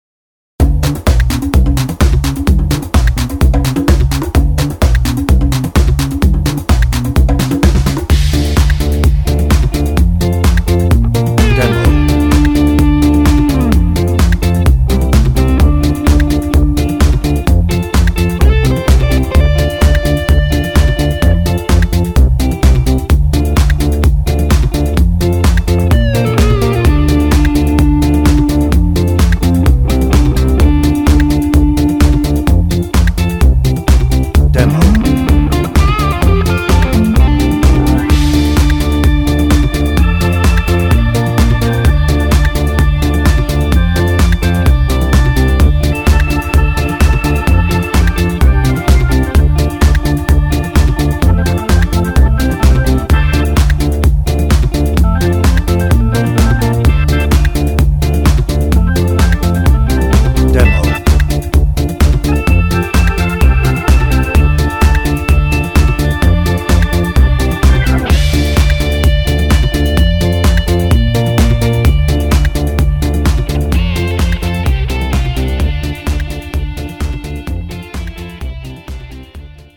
Hoedown
Instrumental